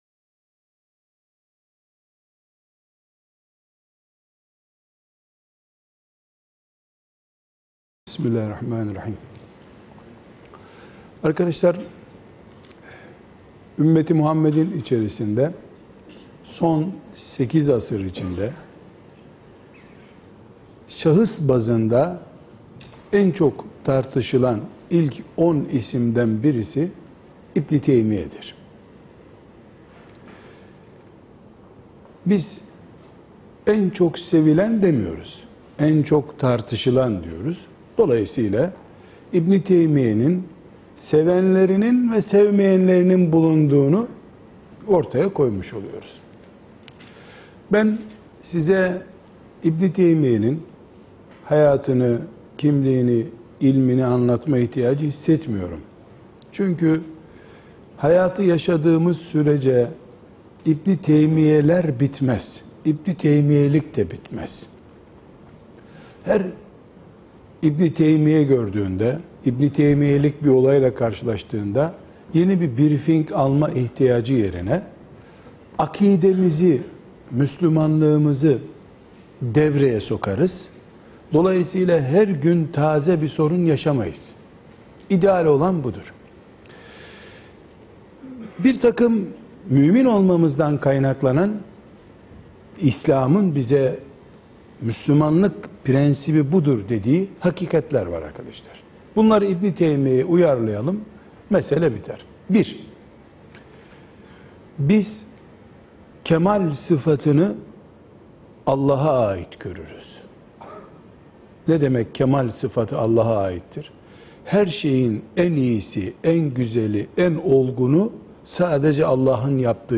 93) İbni Teymiye’yi Doğru Anlamak (Gençlerle Soru-Cevap) | Sosyal Doku TV